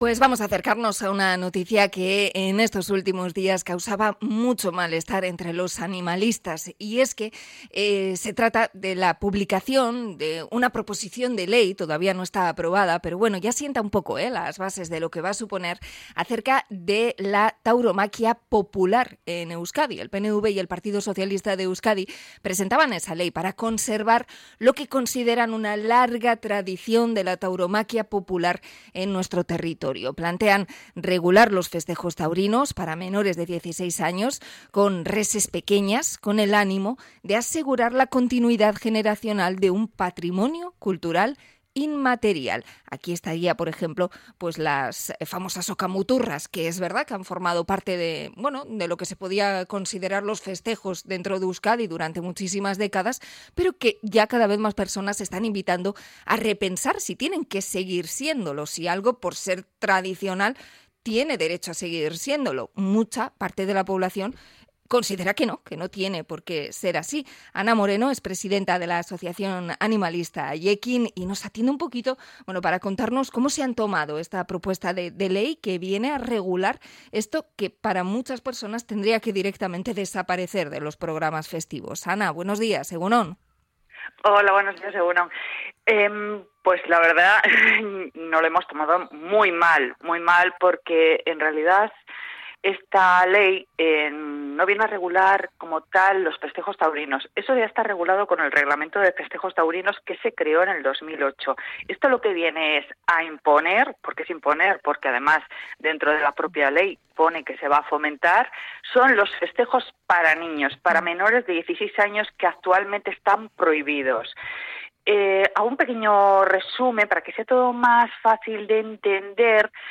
Entrevista con Haiekin sobre la proposición de ley sobre los espectáculos taurinos populares